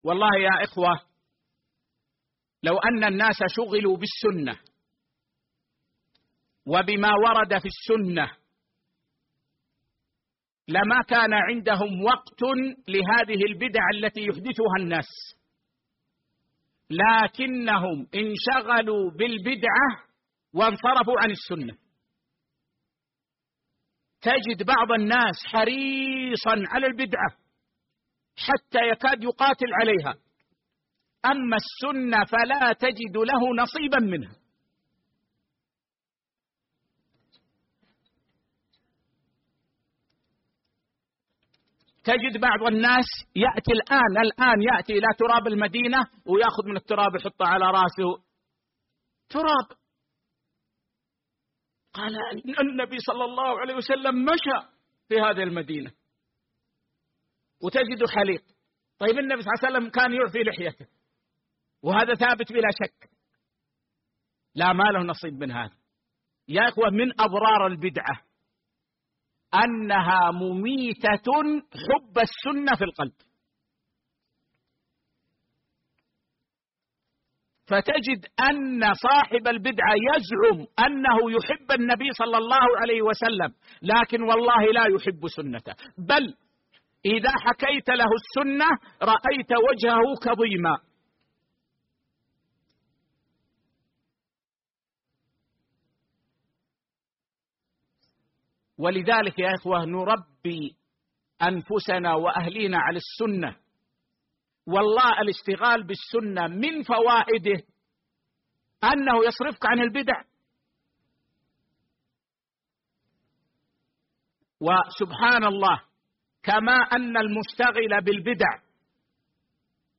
محاضرة
المسجد النبوي